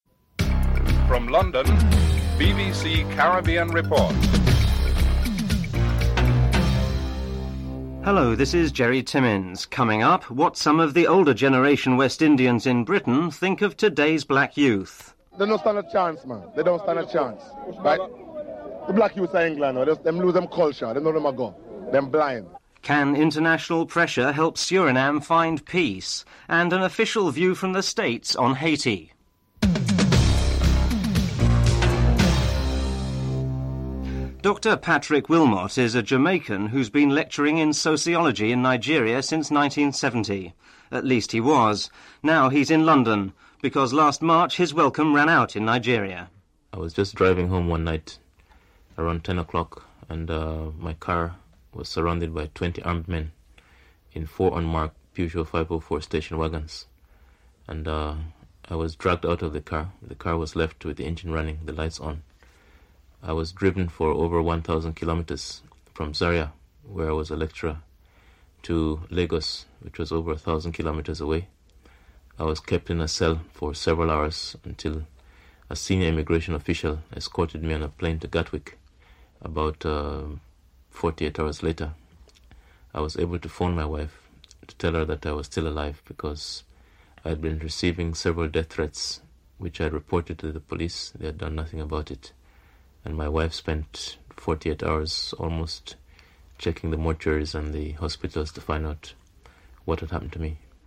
5. Financial Report